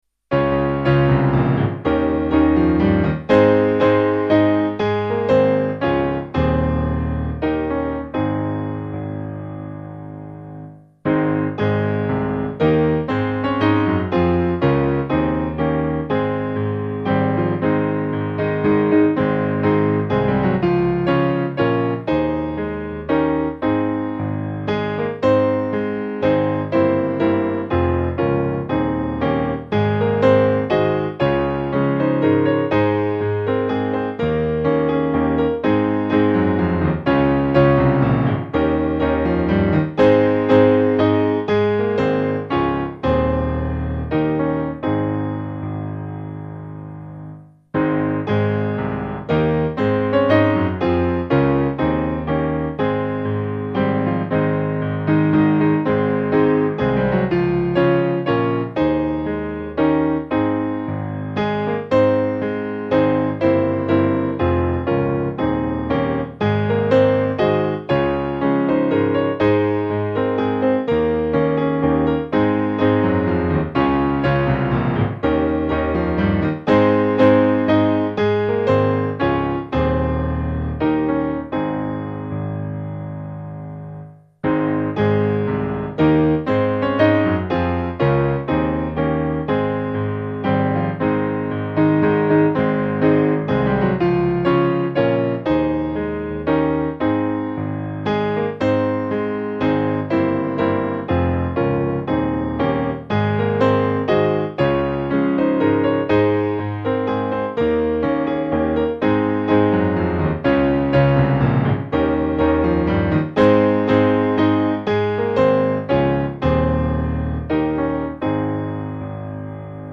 AllHailThePowerOfJesusNamePiano.mp3